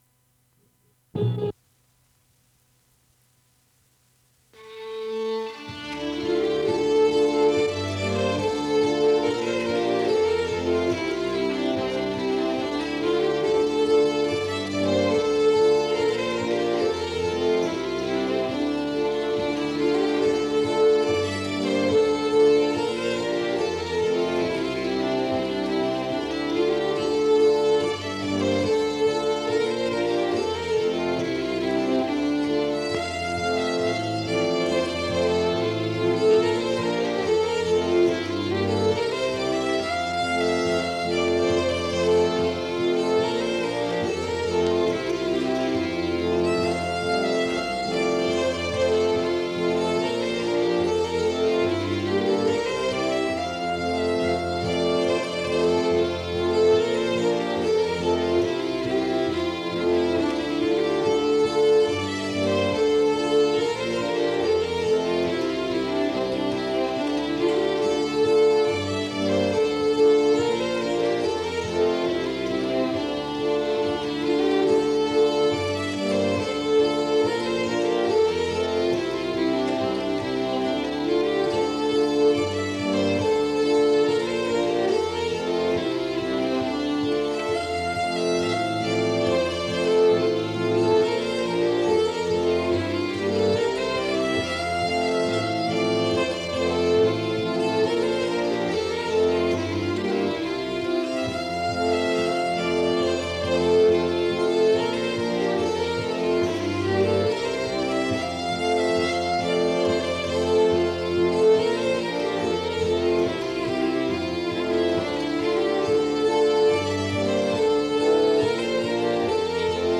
folkmusikgruppen
Schottis